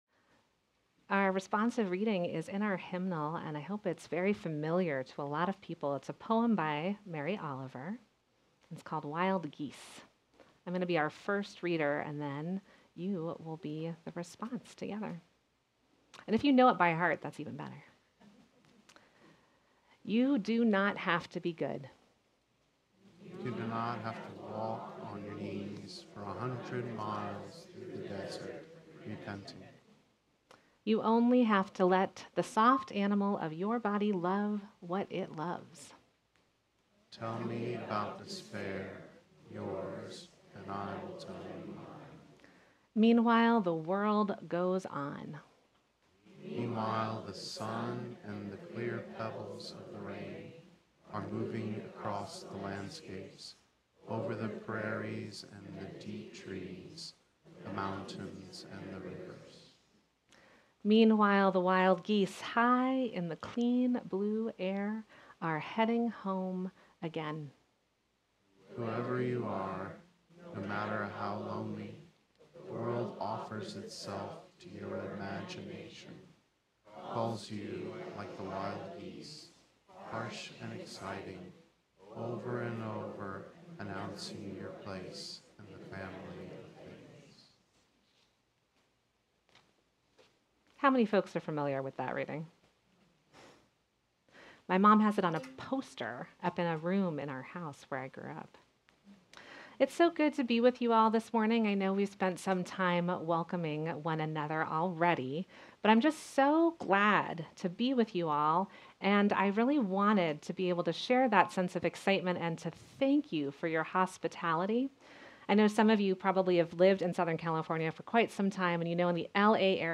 This service invites us to reflect on how intellect and rational thinking can coexist with openness of heart—how we can hold both at once.